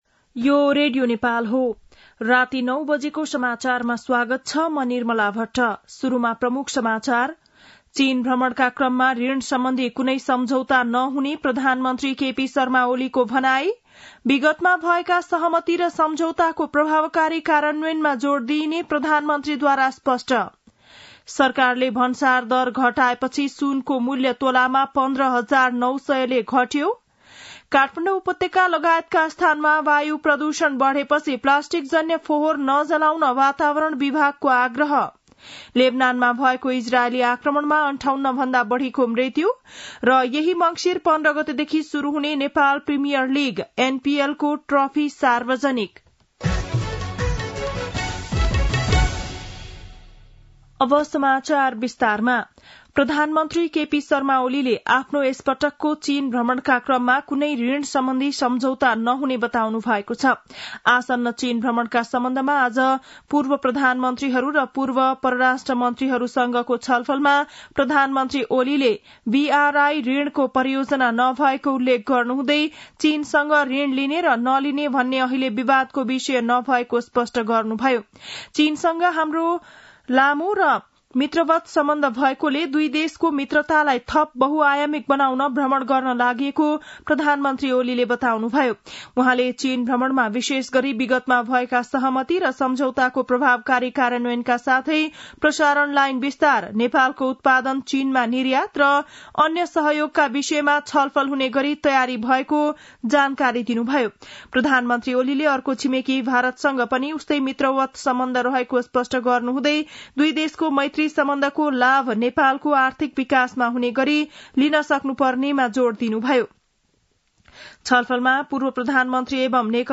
बेलुकी ९ बजेको नेपाली समाचार : ११ मंसिर , २०८१